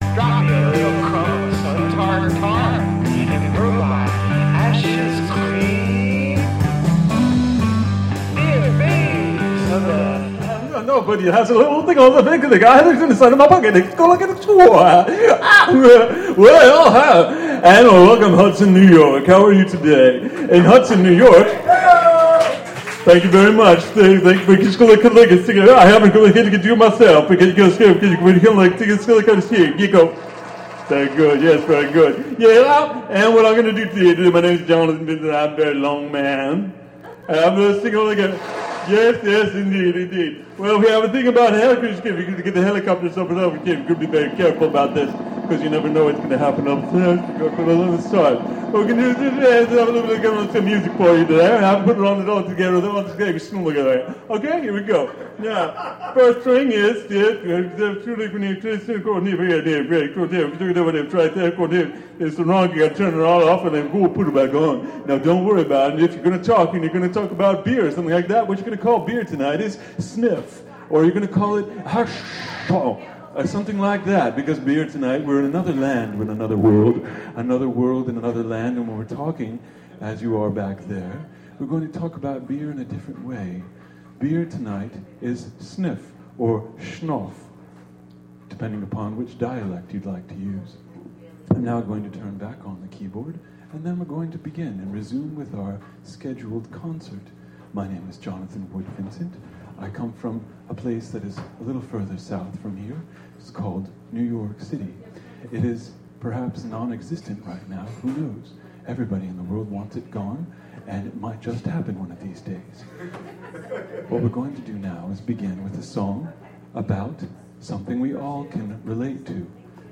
Live performances at Spotty Dog Books & Ale in Hud...